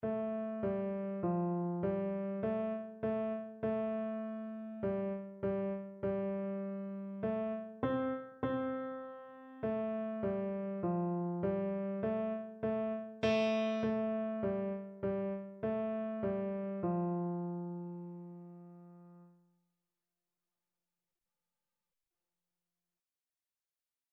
Piano version
4/4 (View more 4/4 Music)
Allegretto
Beginners Level: Recommended for Beginners
Piano  (View more Beginners Piano Music)